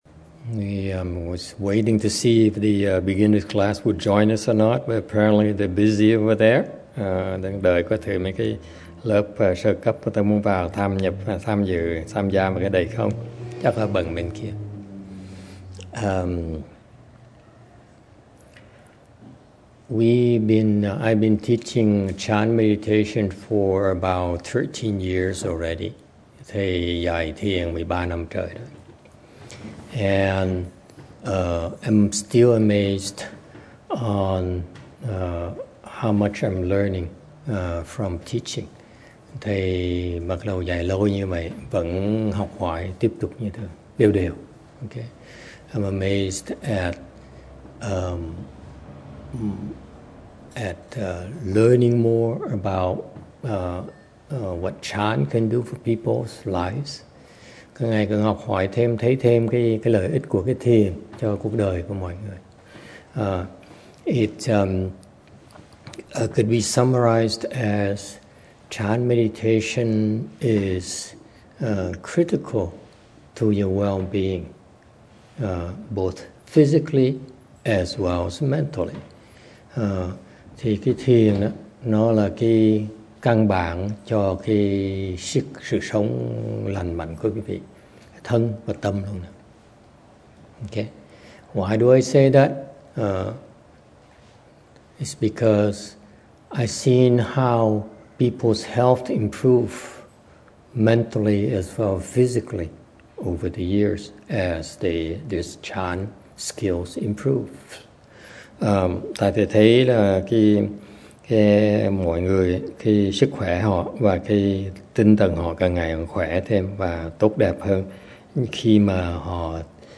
참선 법문: 에너지, 힐링, 우울증 그리고 인내심
장소: 미국 노산사 Lu Mountain Temple